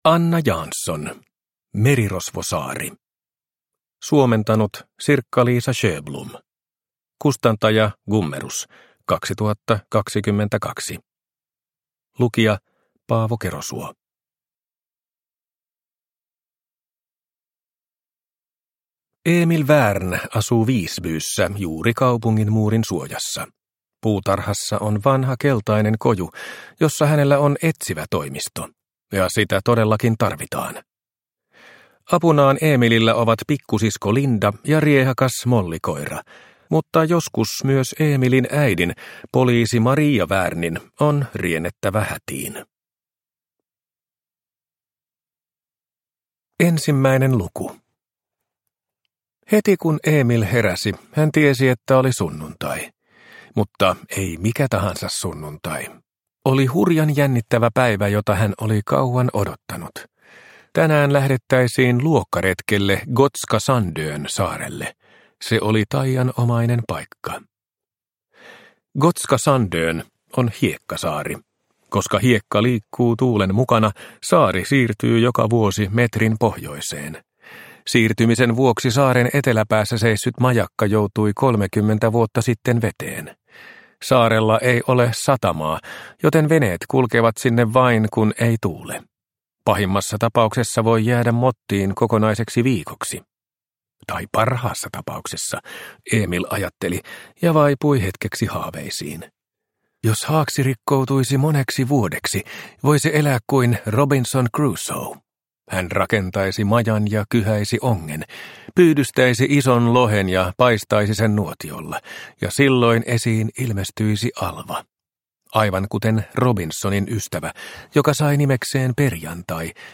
Merirosvosaari – Ljudbok – Laddas ner